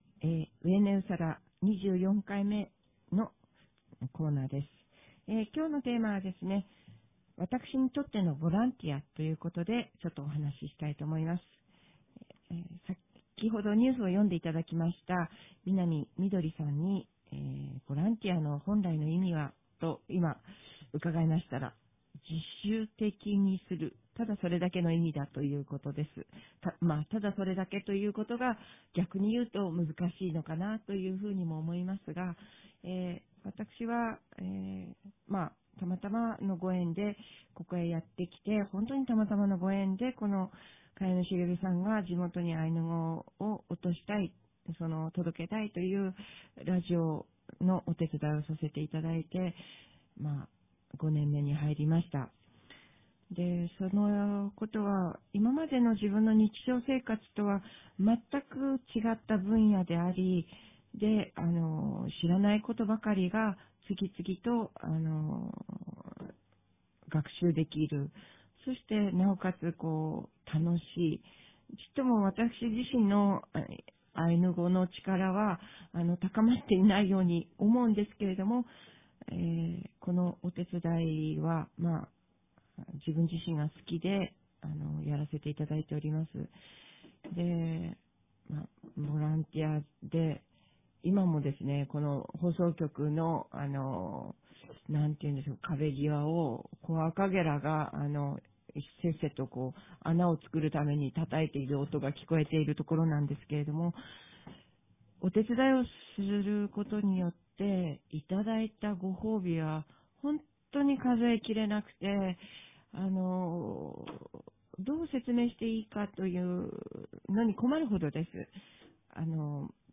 ■二風谷のスタジオから「わいわいがやがや」